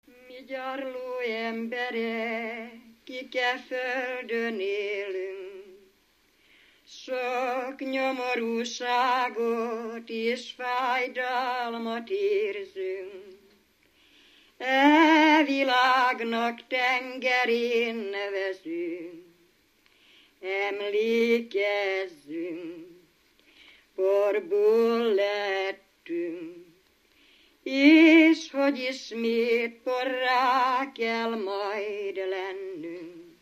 Felföld - Bars vm. - Barslédec
Stílus: 4. Sirató stílusú dallamok